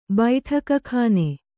♪ baiṭhaka khāne